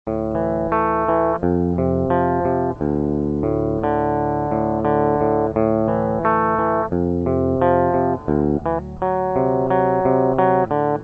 Drop D-Tuning Audio Beispiel: